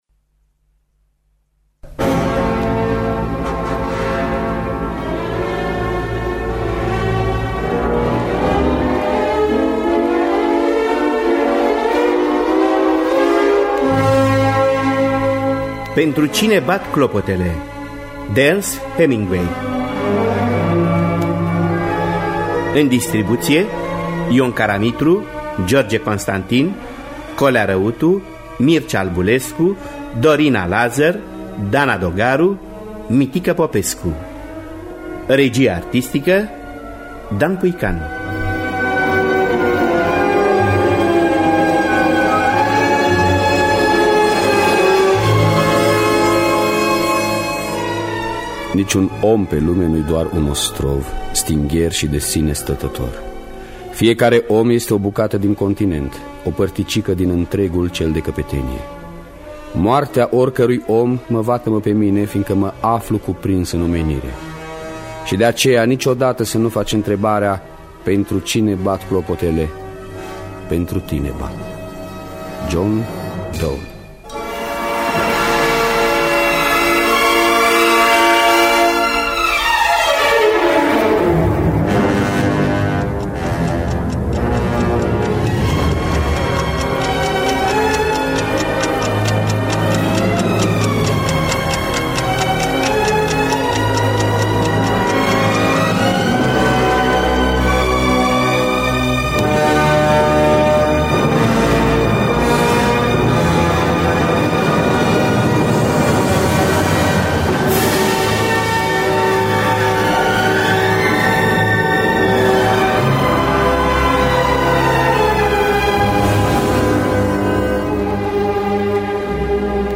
Dramatizarea radiofonică
Înregistrare din anul 1985 (26 aprilie).